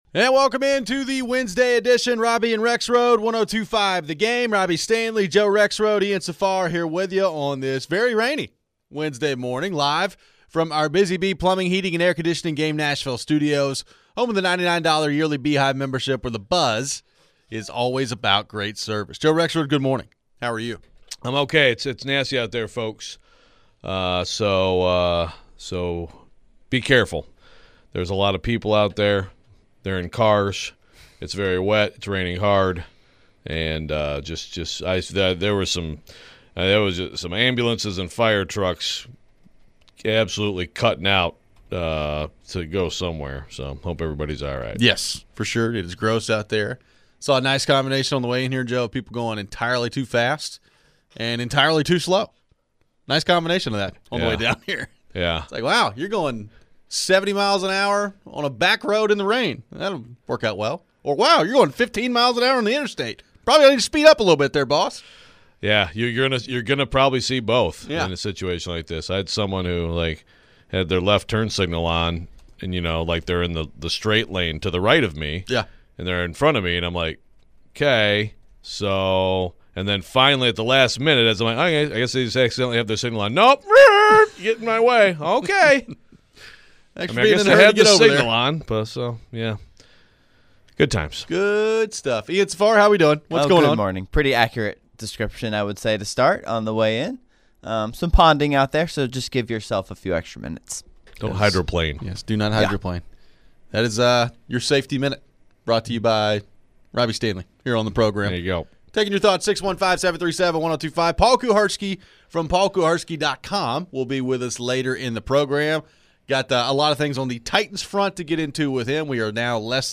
We take your phones.